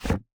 Add mob eating sound and effect
mobs_eat.ogg